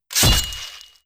Melee Weapon Attack 4.wav